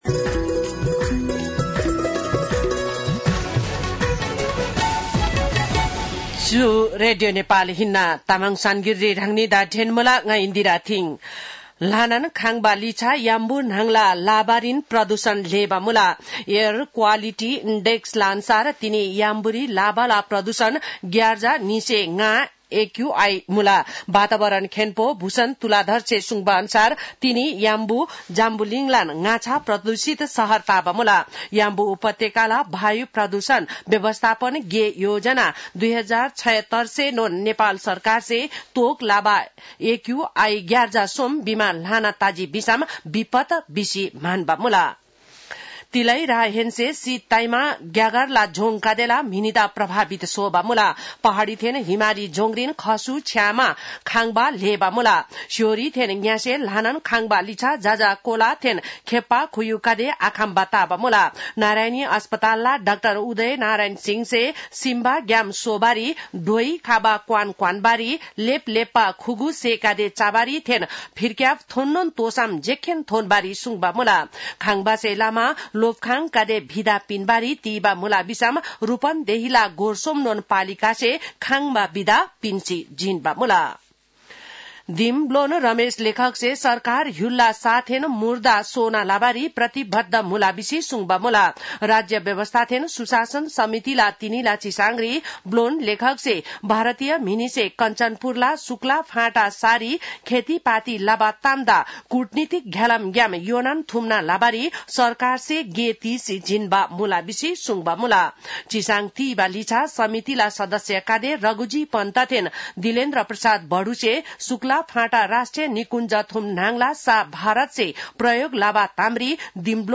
तामाङ भाषाको समाचार : २३ पुष , २०८१